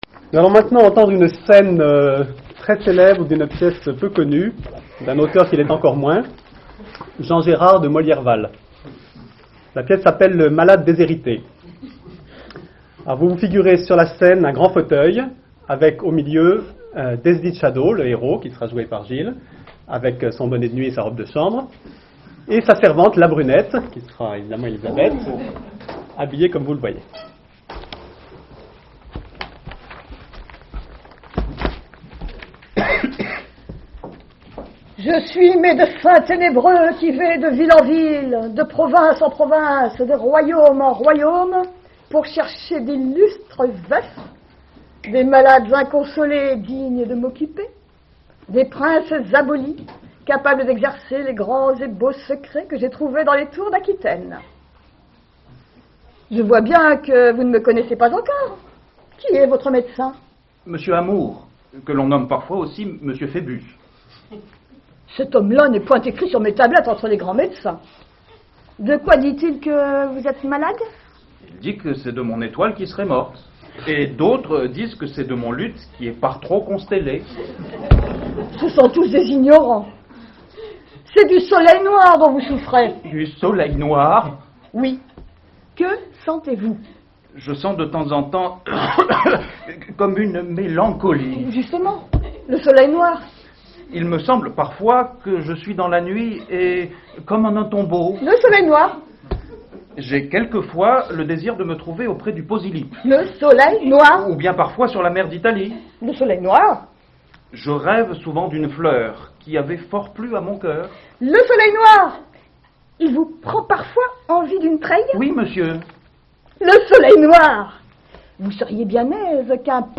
Lecture publique donnée le samedi 27 octobre 2001 par